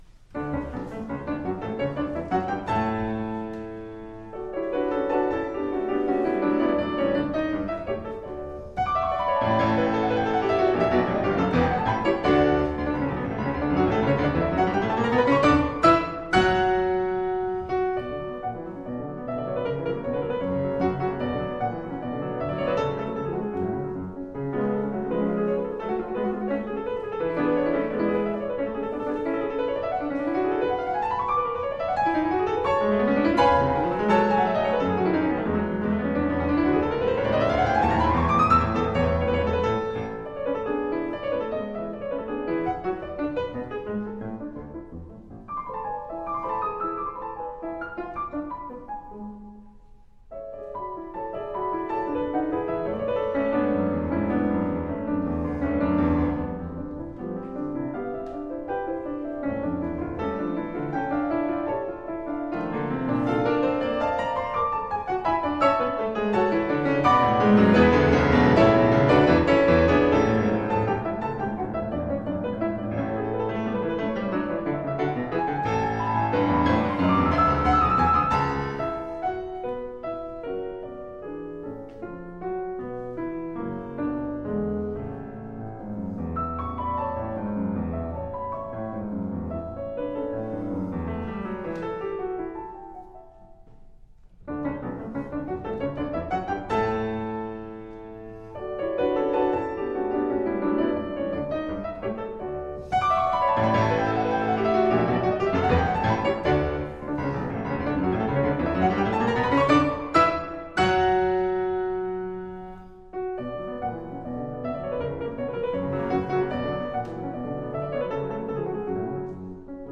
Piano
Style: Classical
Audio: Boston - Isabella Stewart Gardner Museum